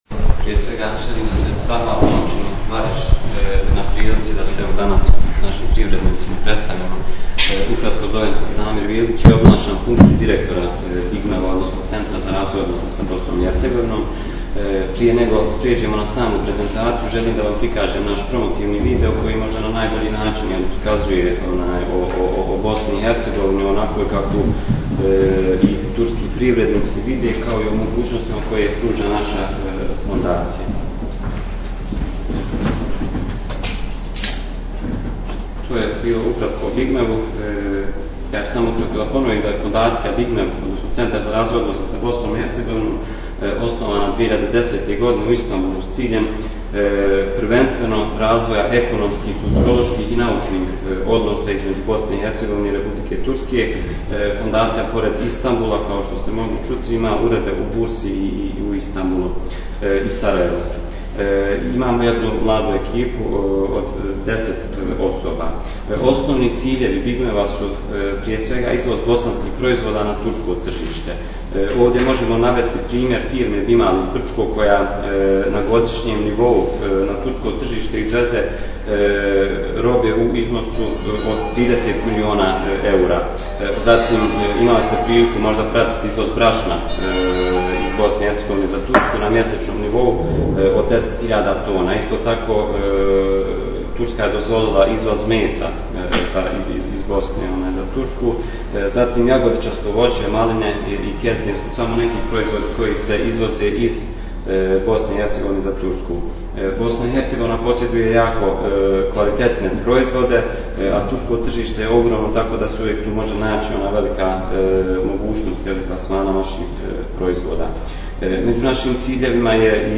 Poslušajte tonski zapis prezentacije Fondacije BIGMEV koja je održana u općini Vareš 27.03.2017. godine ...